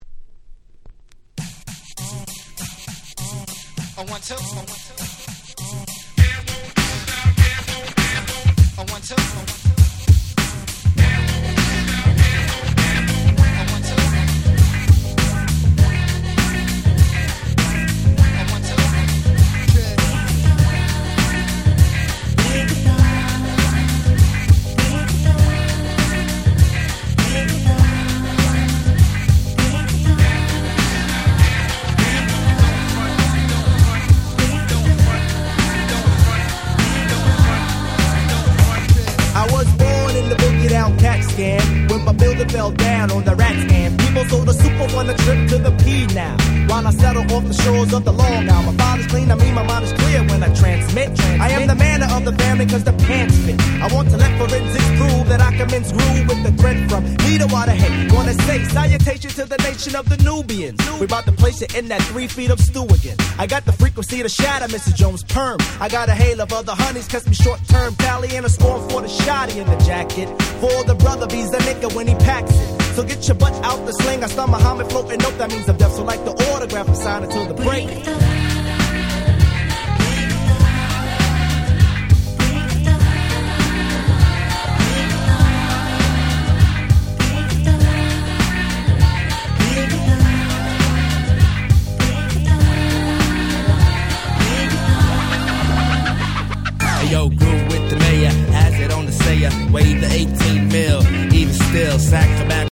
93' Smash Hit Hip Hop !!
90's Boom Bap